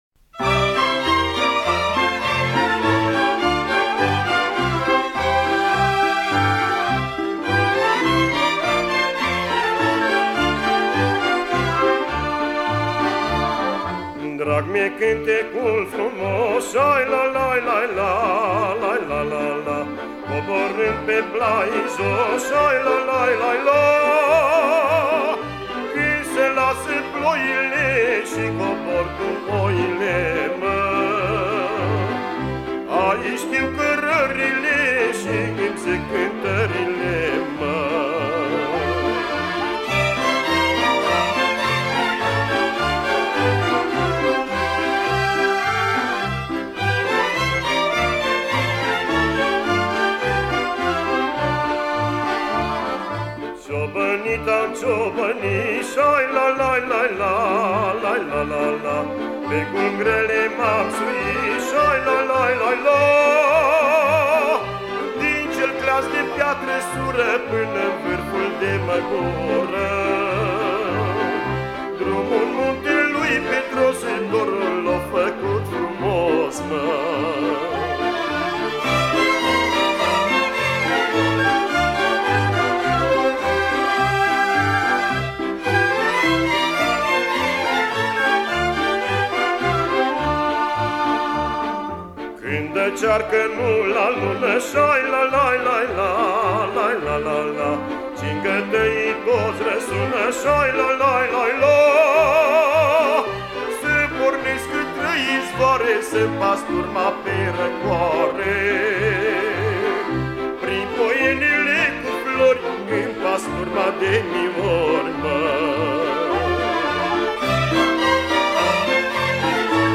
Înregistrări de arhivă